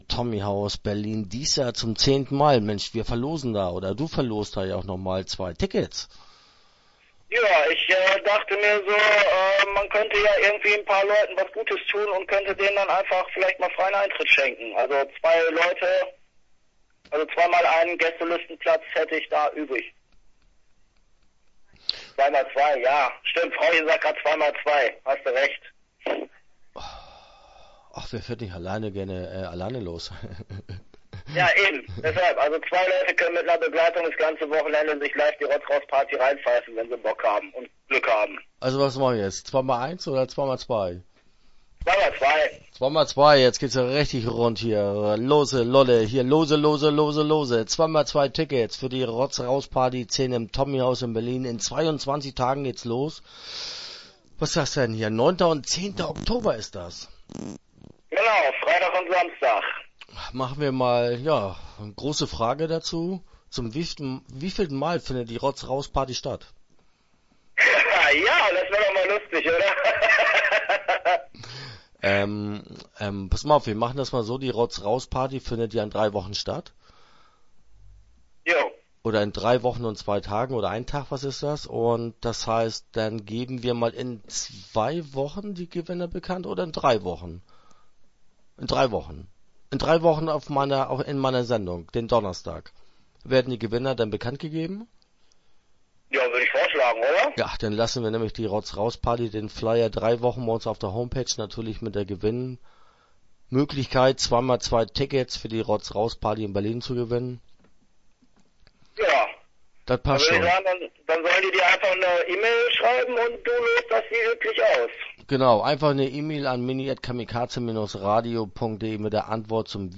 Rotz Raus Party - Interview Teil 1 (7:52)